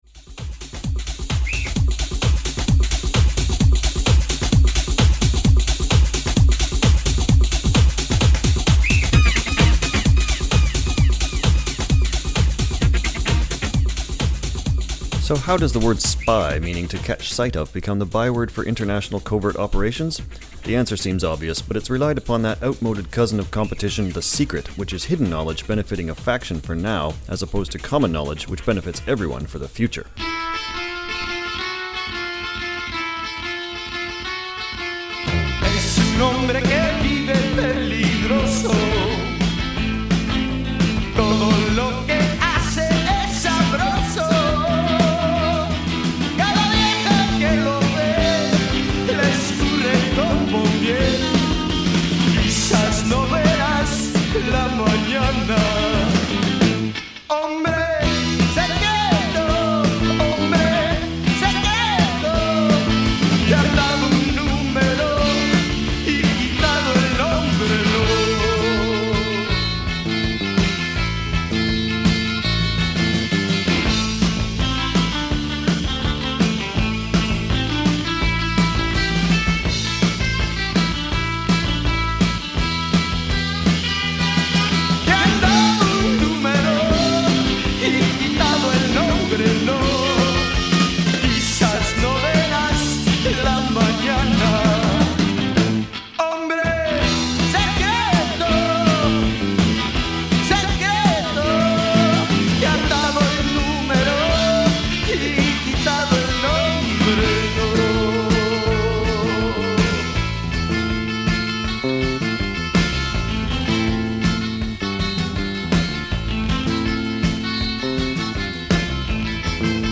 mixed-genre for slippery backbones